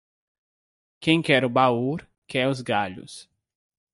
Pronounced as (IPA) /baˈu/